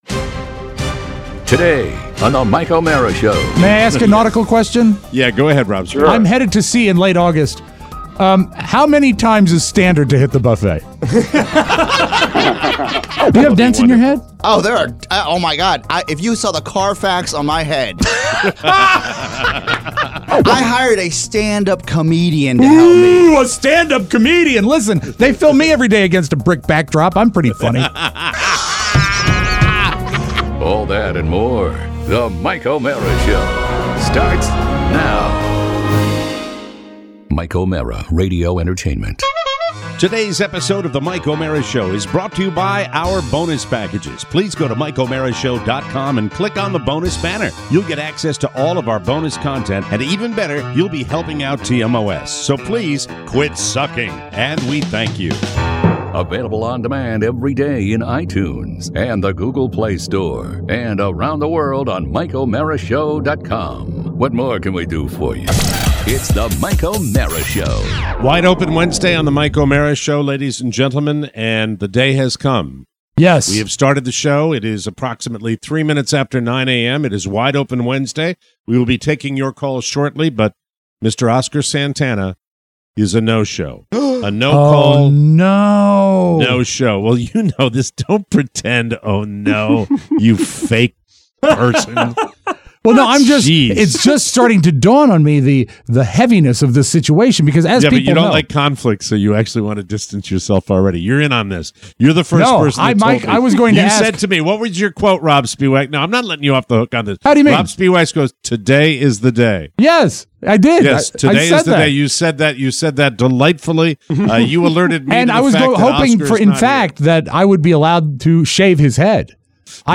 Today, we entertain your calls.